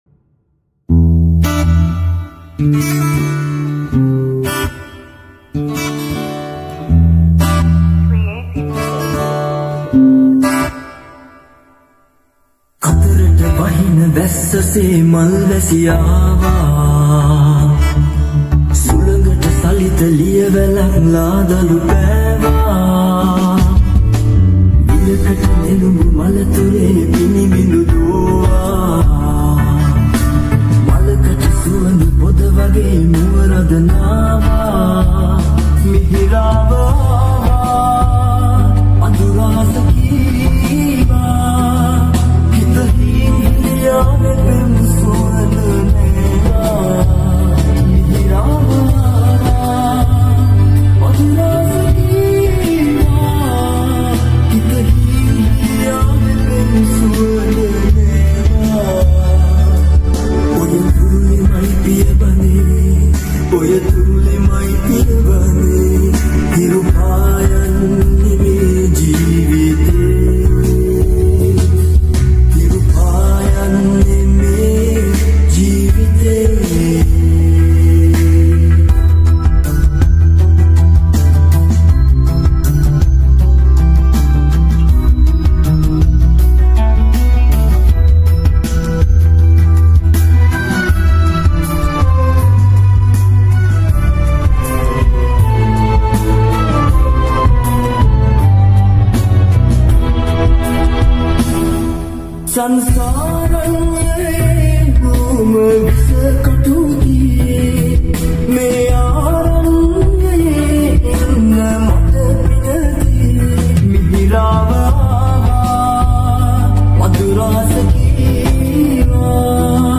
sri 8d music New Song